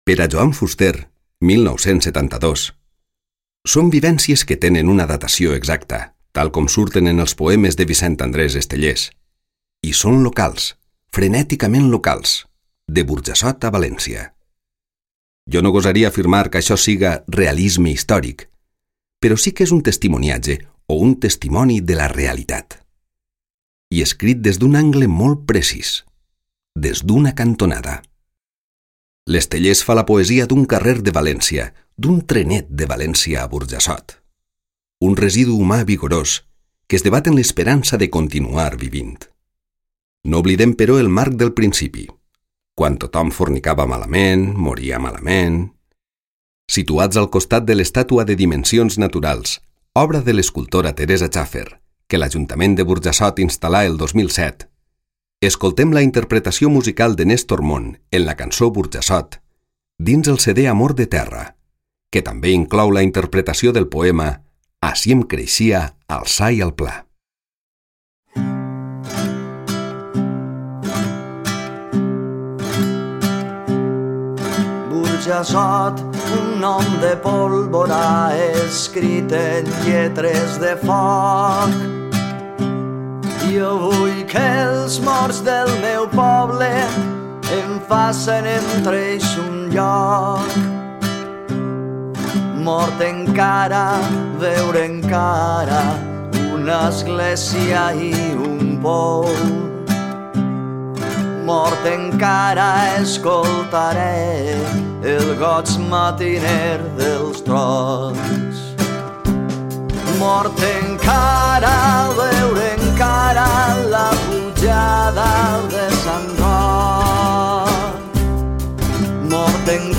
Explicació en àudio: